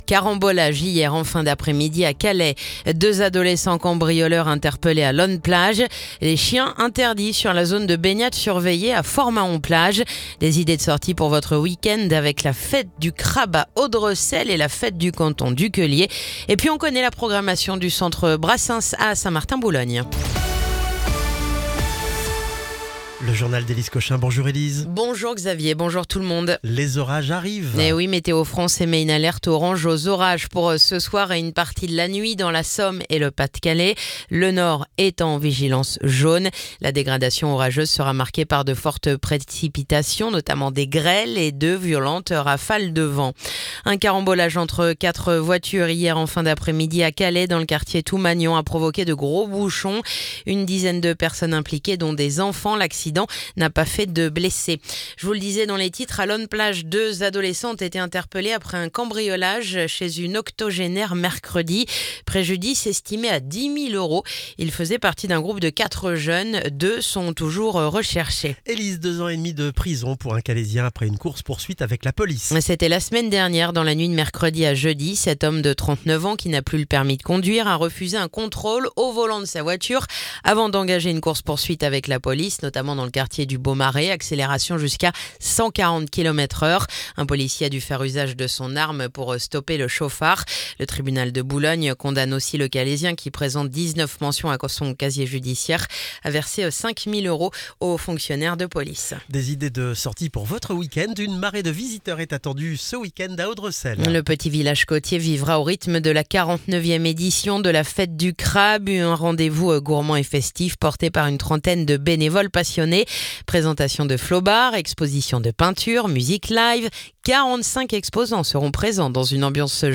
Le journal du vendredi 13 juin